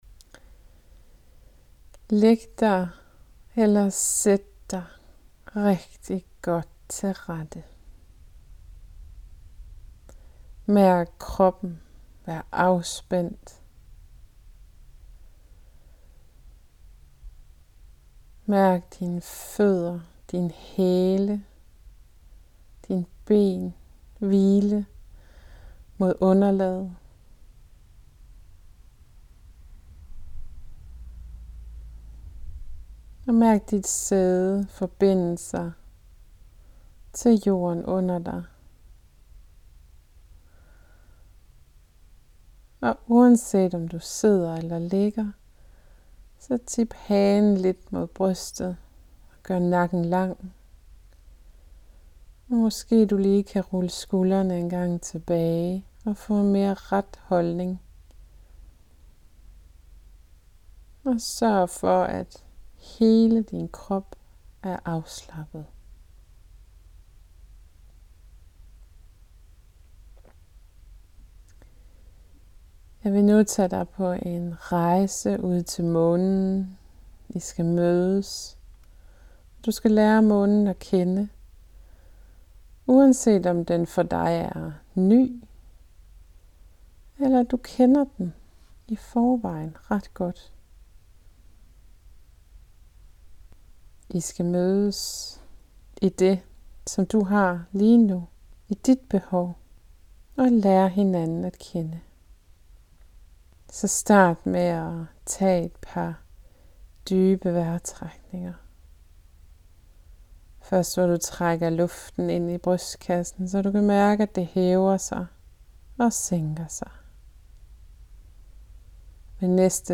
Denne guidede meditation har jeg lavet til dig, som har brug for at forbinde dig med månen. Du kommer til at møde din Måne, og mærke hvordan månen resonerer i dig.
måne_meditation.mp3